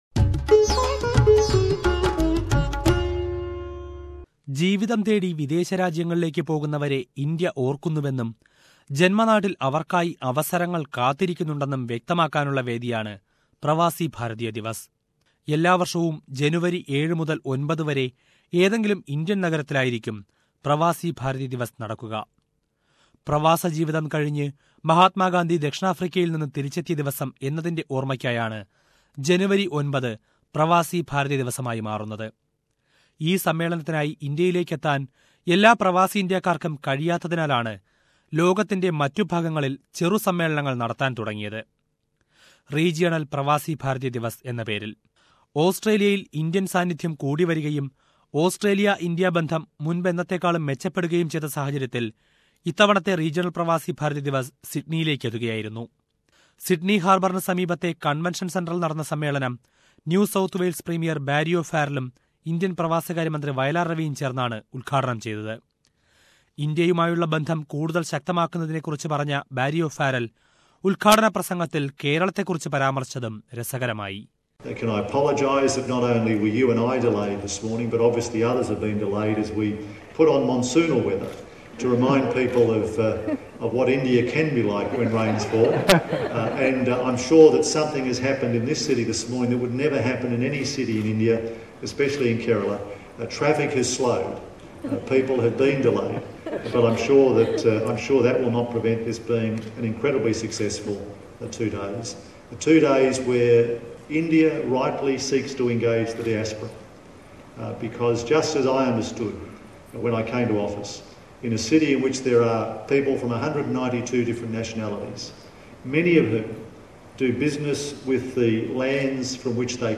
Let us listen to a report on SBS Malayalam Radio